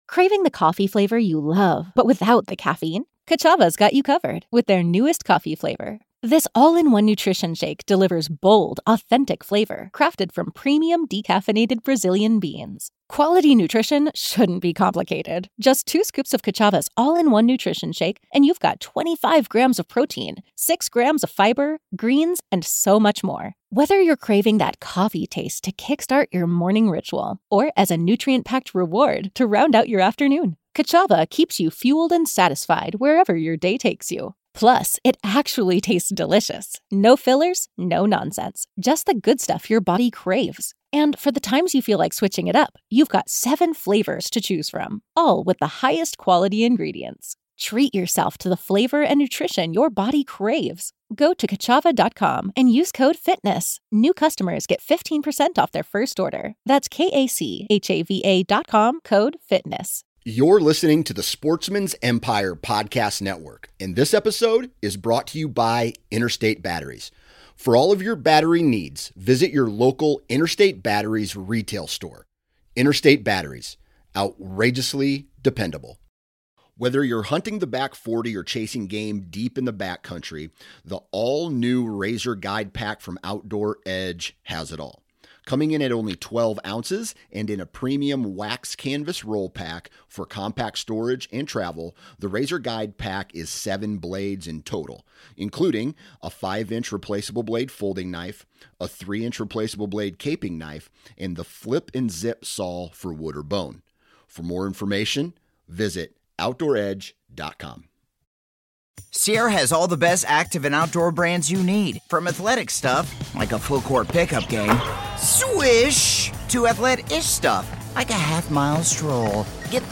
The Limbhanger Turkey Hunting Podcast brings opinions and discussions from all aspects of the turkey hunting community. From legendary turkey hunters who hunted in military fatigues, to the modern day hunter embracing technology while still maintaining the traditions passed down for generations, all are welcome at this roundtable discussion about one of the wariest creatures in North America, the wild turkey.